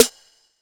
RYET_SNR.wav